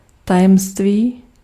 Ääntäminen
France (Paris): IPA: [sø.kʁɛ]